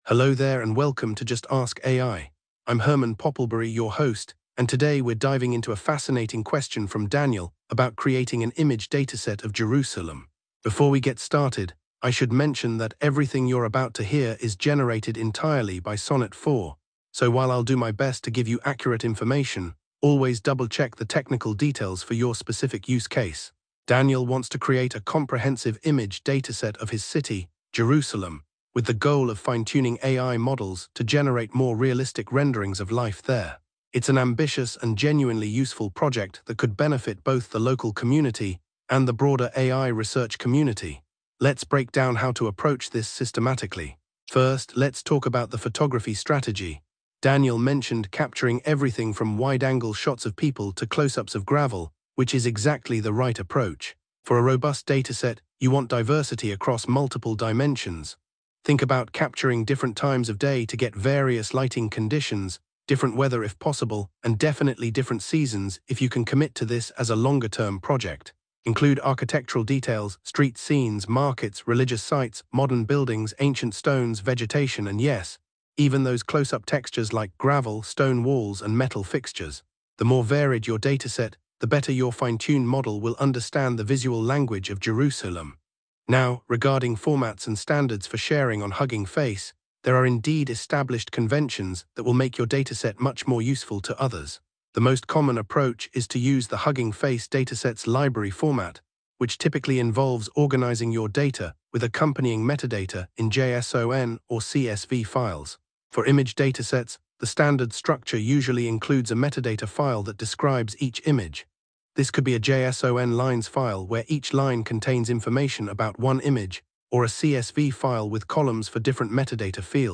AI-Generated Content: This podcast is created using AI personas.
Hosts Herman and Corn are AI personalities.